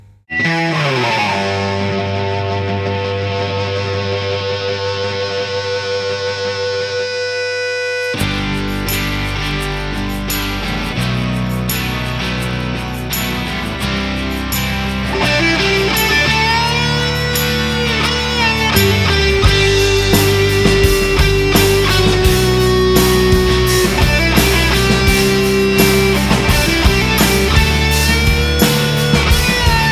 • Indie Rock